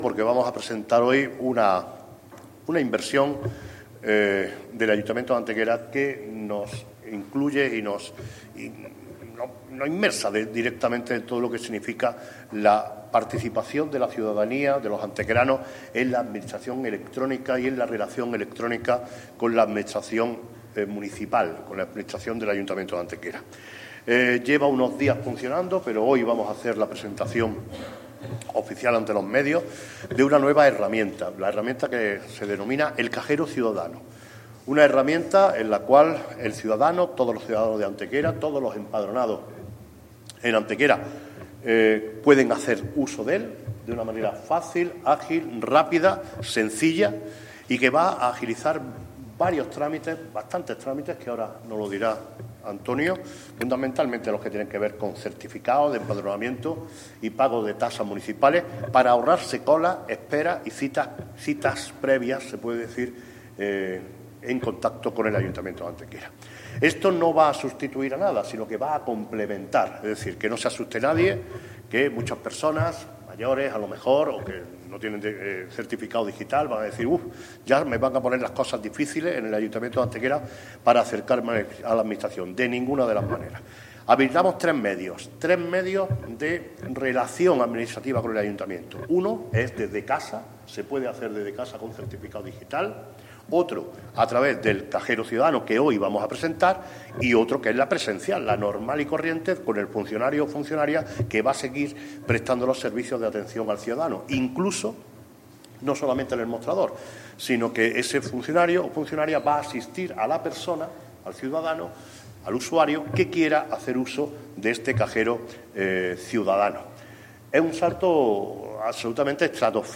El alcalde de Antequera, Manolo Barón, y el teniente de alcalde delegado de Hacienda y Nuevas Tecnologías, Antonio García Acedo, han presentado ante los medios de comunicación el nuevo "Cajero Ciudadano", herramienta informática presencial ubicada en la planta baja del Ayuntamiento y que permite ya facilitar varios trámites administrativos.
Cortes de voz